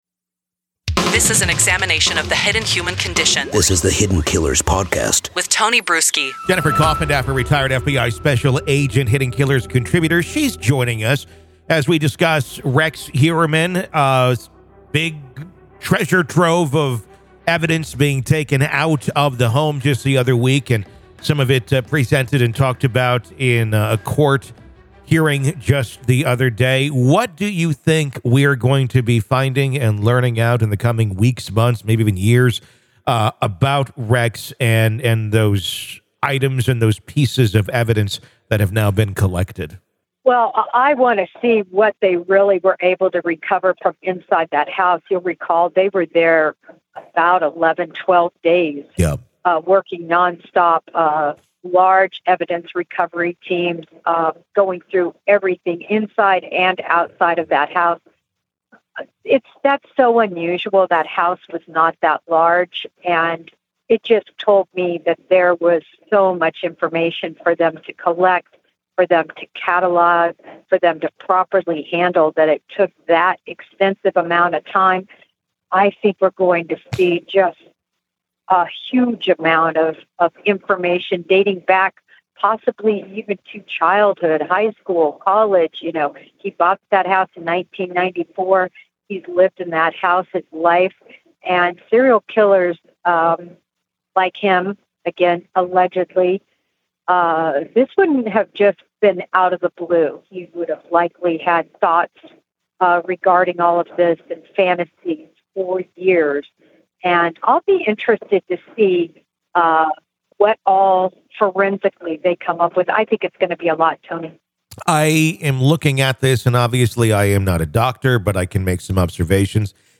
The duo dissected the clues and potential evidence found at the suspect's home following an exhaustive 12-day investigation by law enforcement.